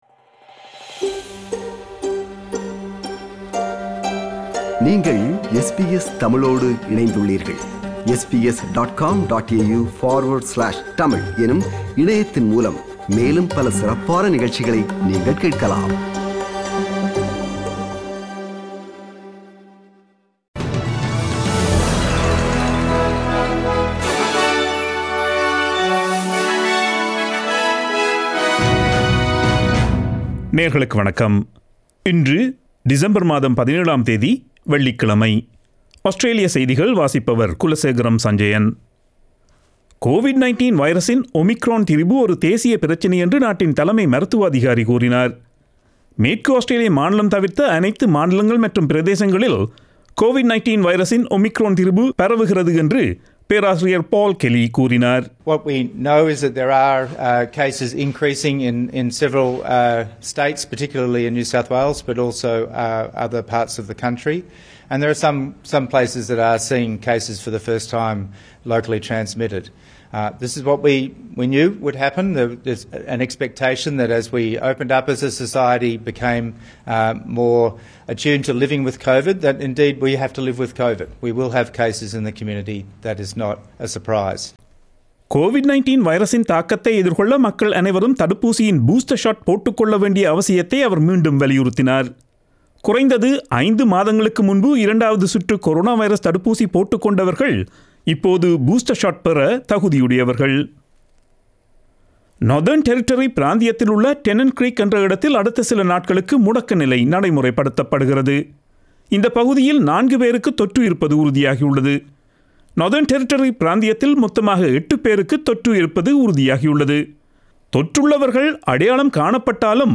Australian news bulletin for Friday 17 December 2021.